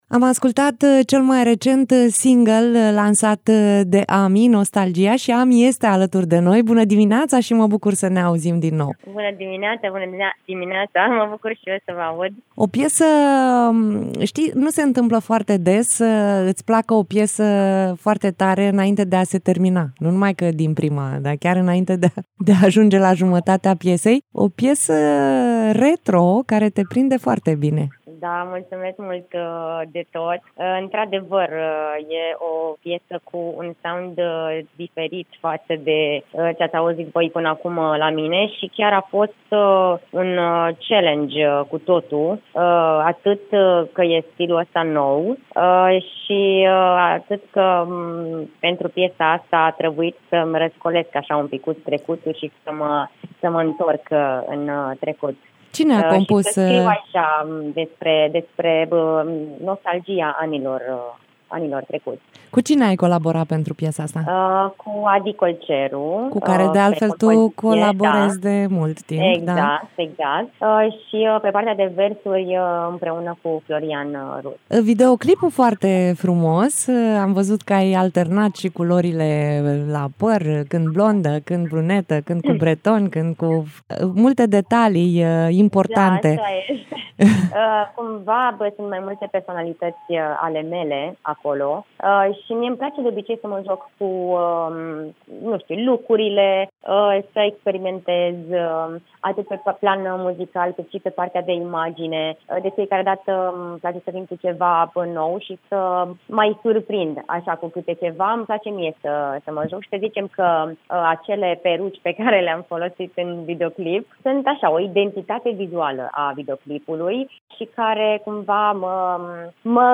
(INTERVIU) AMI, la Bună Dimineața (21.10.2023)